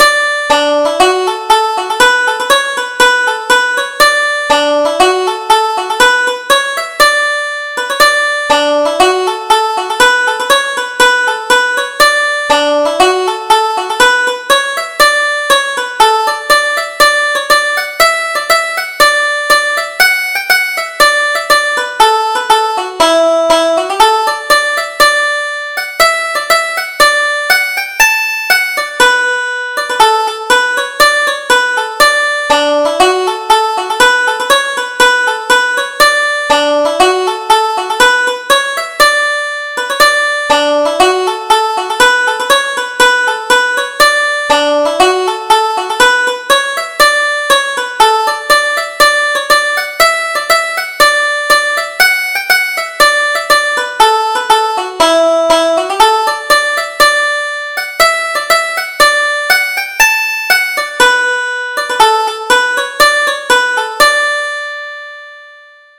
Up on the Wagon: Polka
Irish Traditional Polkas